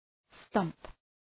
Shkrimi fonetik {stʌmp}